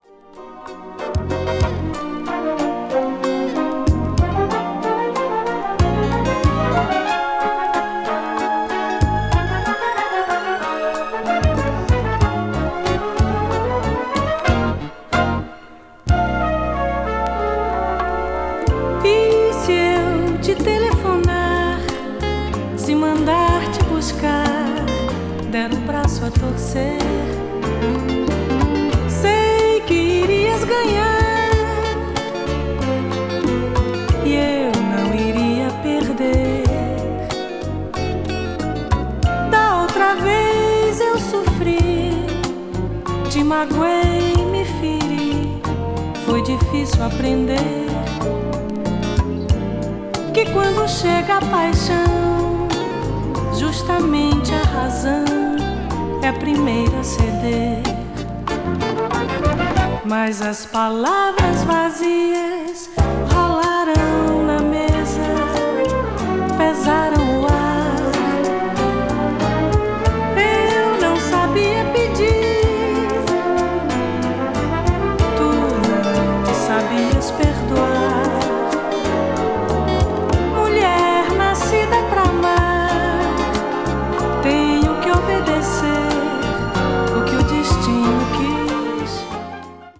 a gentle and breezy composition
which creates a wonderfully calming atmosphere.